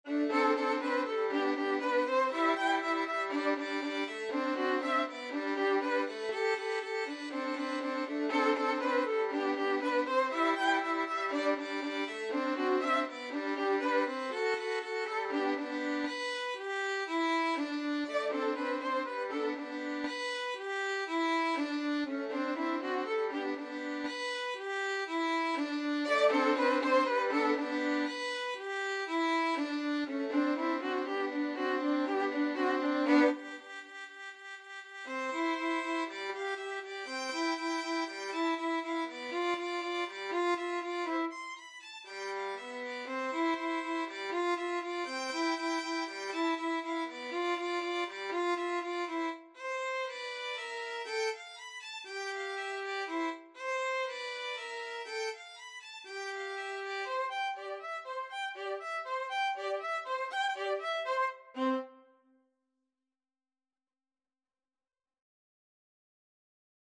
Allegro = 120 (View more music marked Allegro)
Violin Duet  (View more Easy Violin Duet Music)
Classical (View more Classical Violin Duet Music)